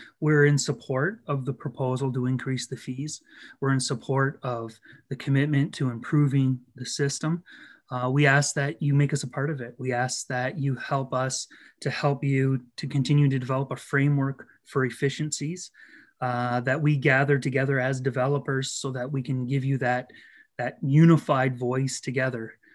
County council passed a new planning fee structure and planning process changes geared towards efficiency.